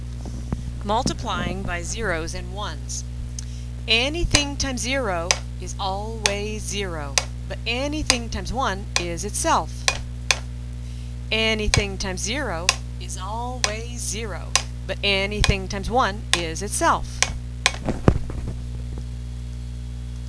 Ditty Multiplying by Zeros and Ones